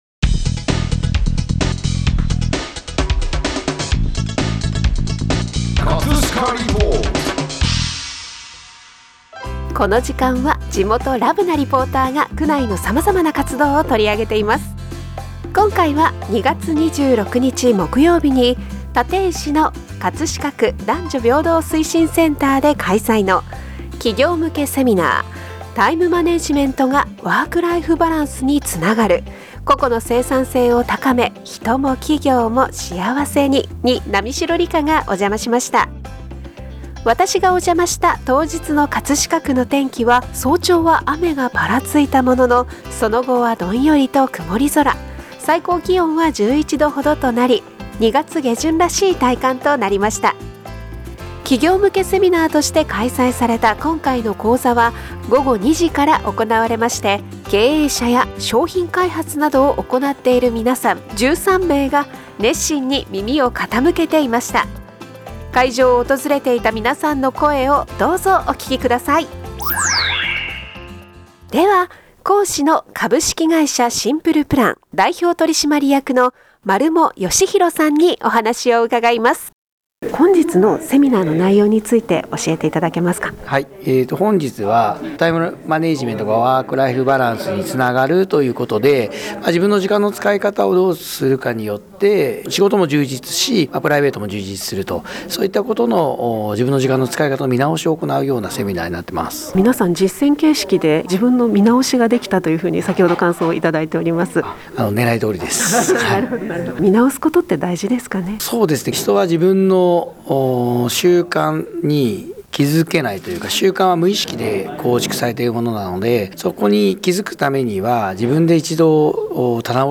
【葛飾リポート】 葛飾リポートでは、区内の様々な活動を取り上げています。
企業向けセミナーとして開催された今回の講座は、午後2時から行なわれ、経営者や商品開発などを行っている皆さん13名が熱心に耳を傾けていました。 会場を訪れていた皆さんの声をどうぞお聴きください。